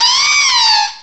cry_not_servine.aif